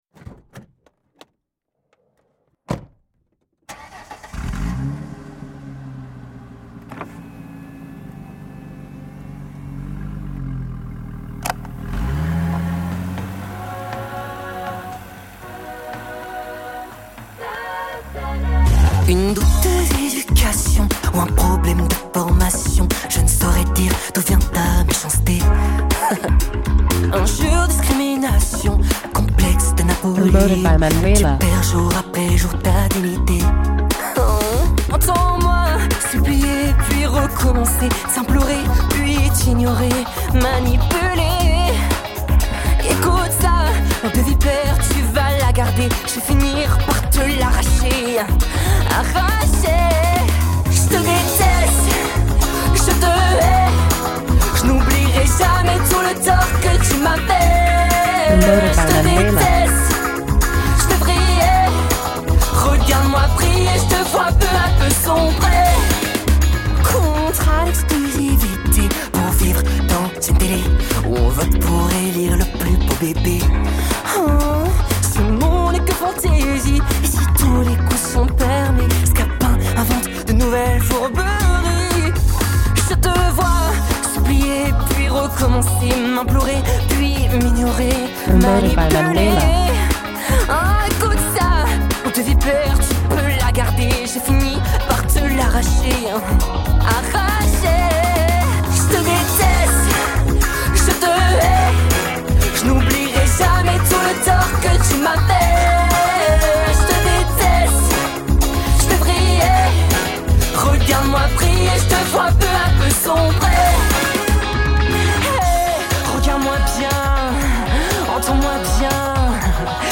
single piquant et catchy